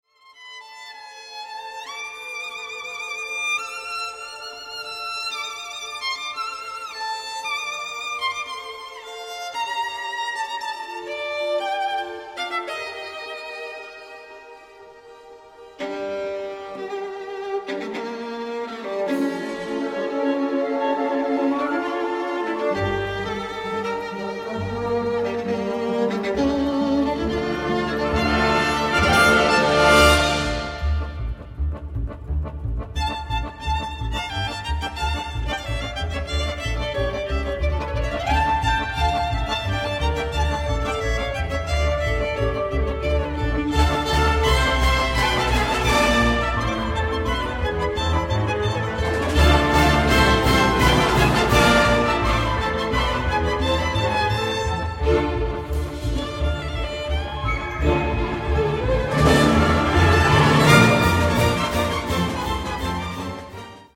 classical crossover